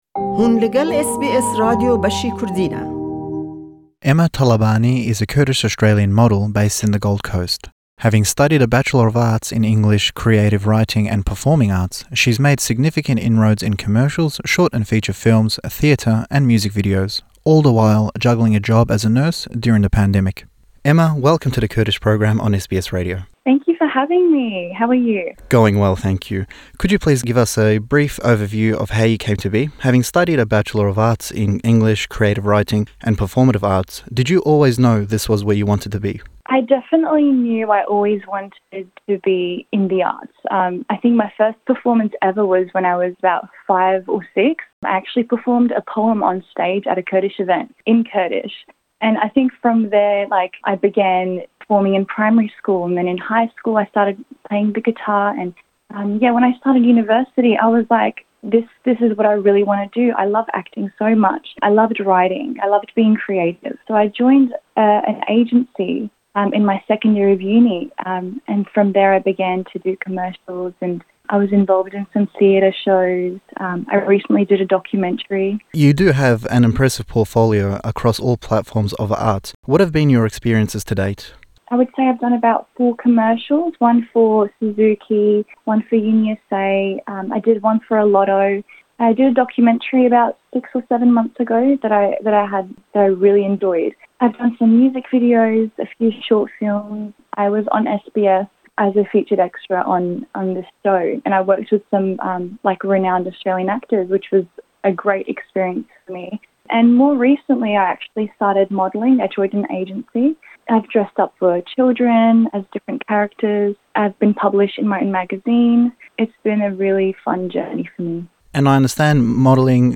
Lem lêhdwane be zimanî Înglîzî